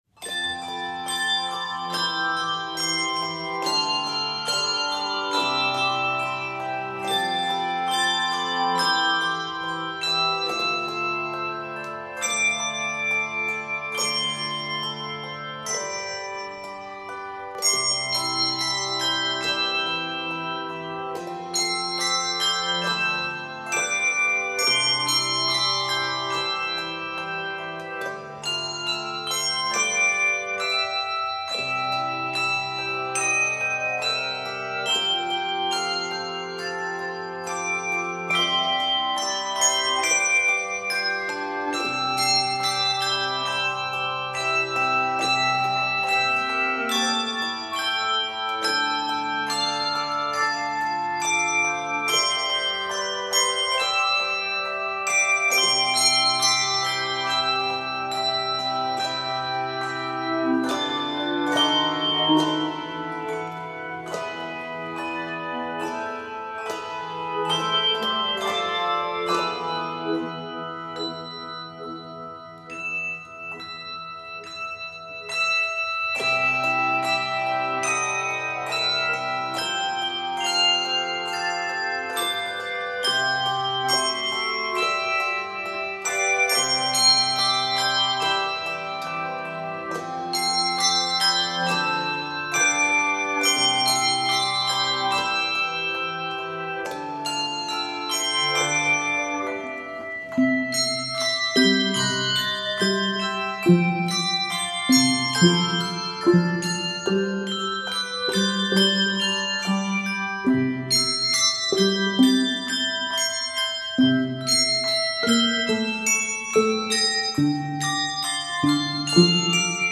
Scored in C Major. 69 measures.